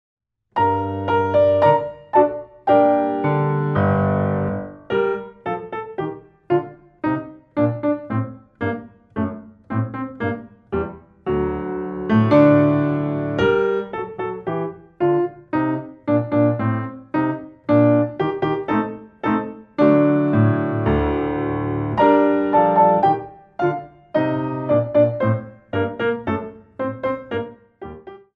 2 bar intro 4/4
32 bars